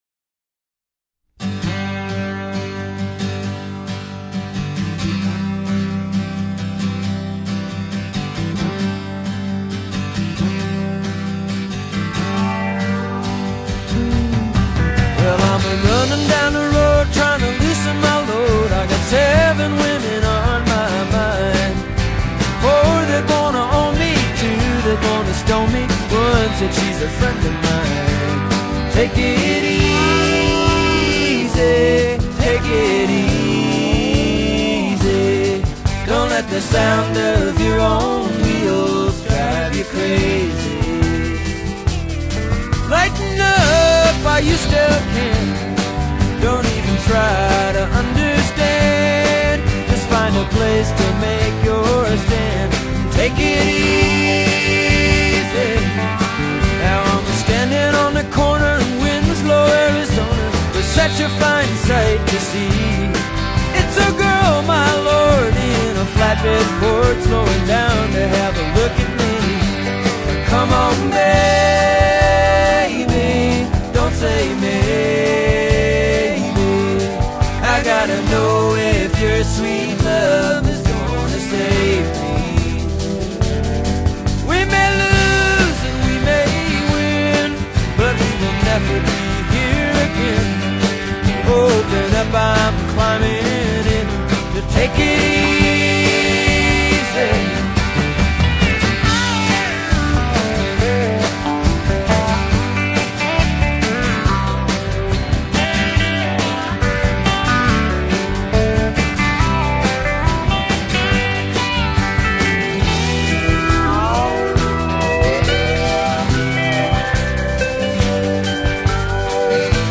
pedal steel guitar
acoustic guitar